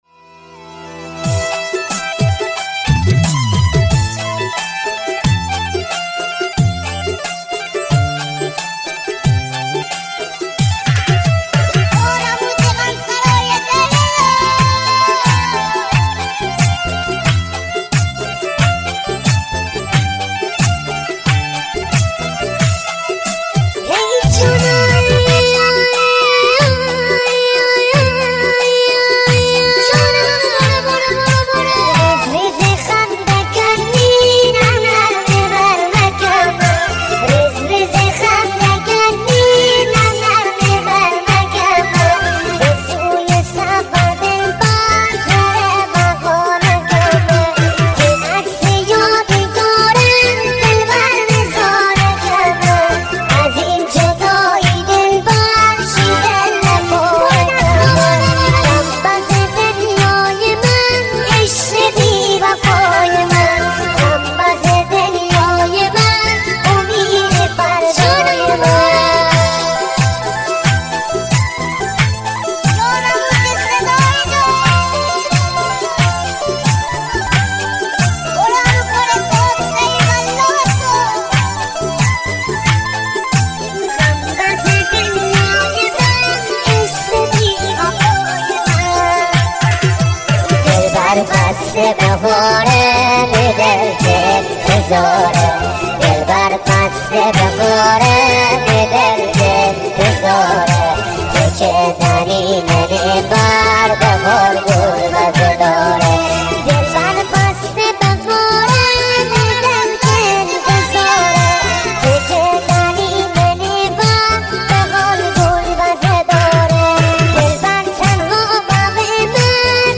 آهنگ مازندرانی
ریمیکس با صدای بچه بچه گانه نازک شده